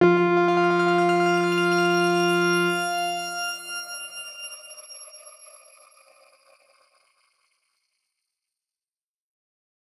X_Grain-F3-ff.wav